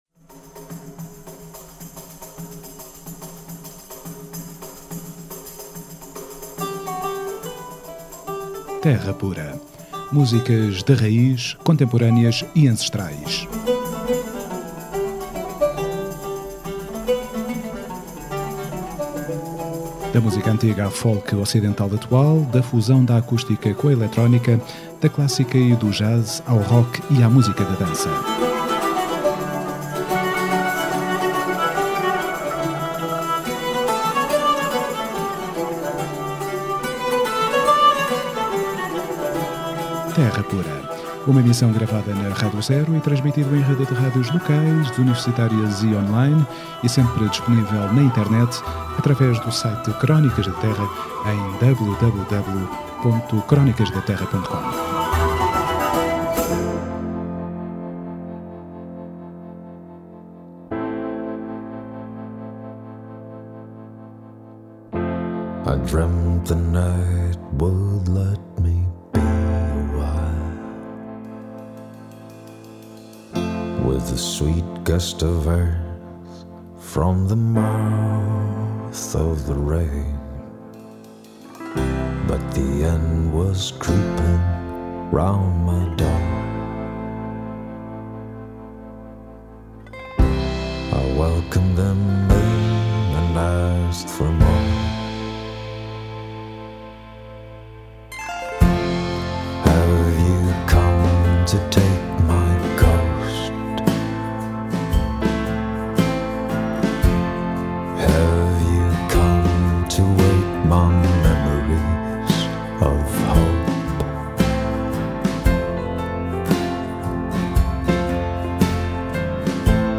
Terra Pura 15DEZ14: Entrevista a Jigsaw